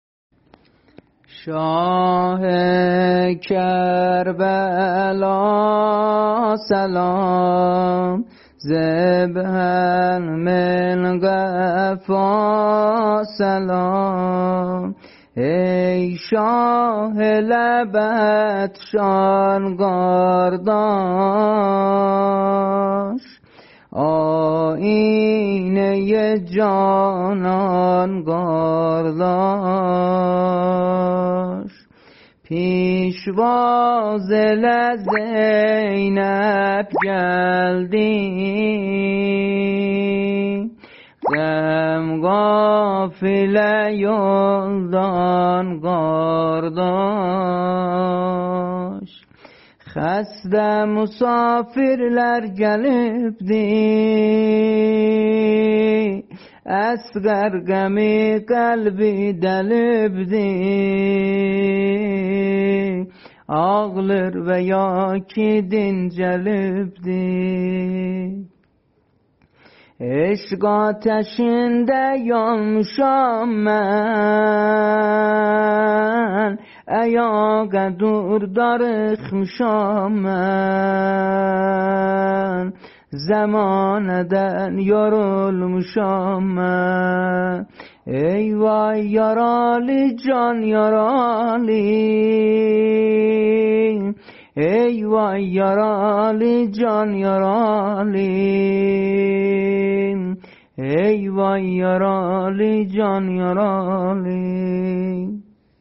دستگاه شور ...